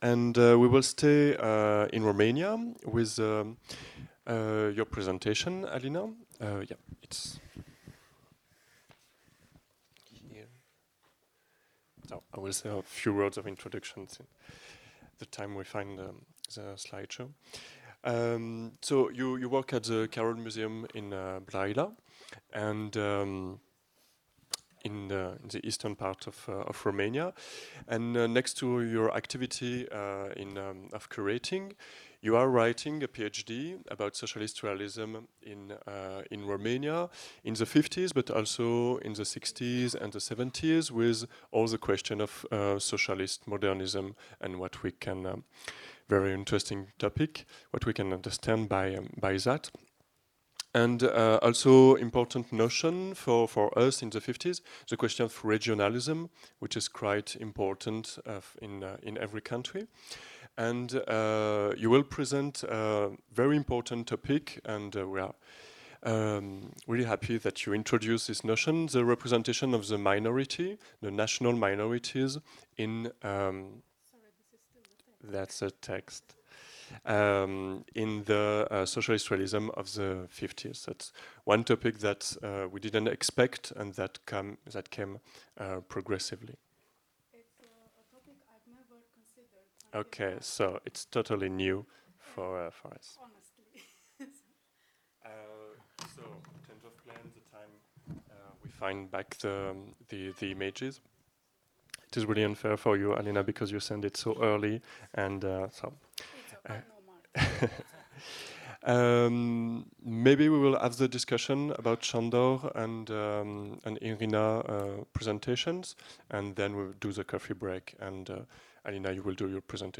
Pierwsza dyskusja w ramach sesji Twarze klasy robotnicze: robotnicy i chłopi, mężczyźni i kobiety, mniejszości narodowe Prowadzenie